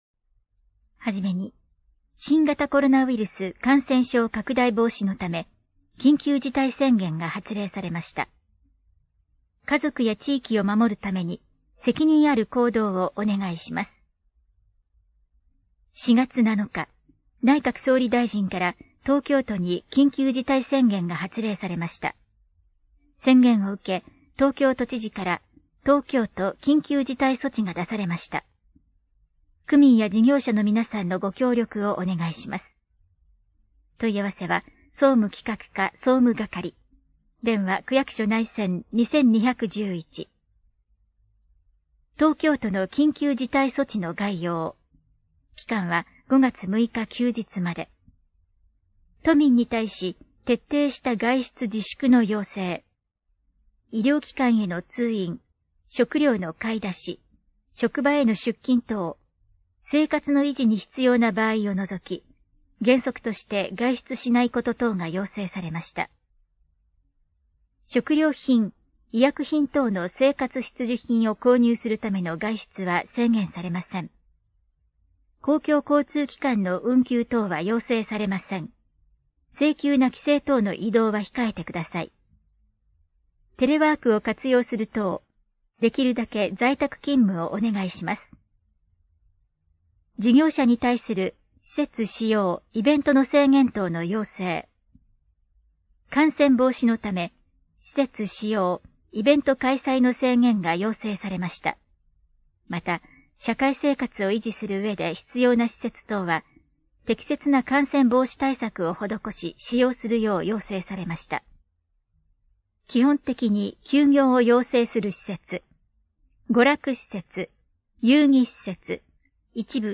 トップページ > 広報・報道・広聴 > 声のあらかわ区報 > 2020年 > 4月 > 2020年4月10日号